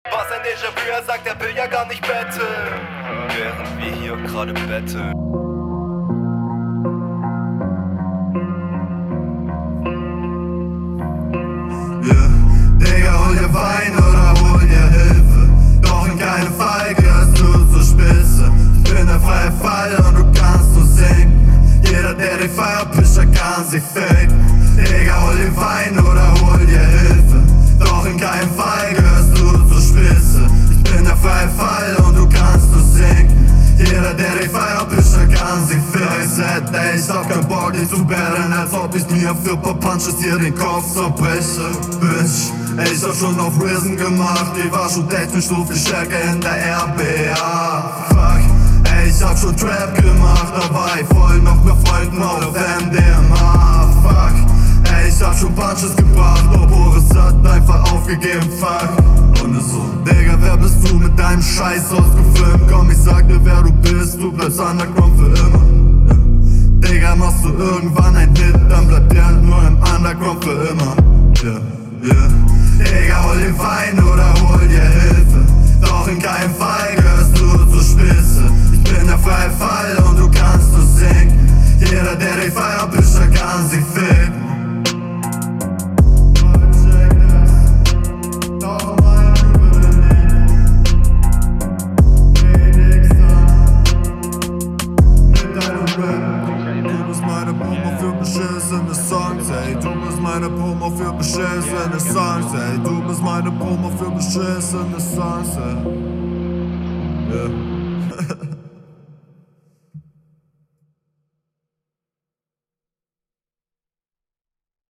Hook find ich direkt richtig cool. Hat halt mega diesen Lil Peep Vibe.
Ich finde den Stimmeinsatz hier relativ nice, auch die Delivery finde ich sehr solide, er …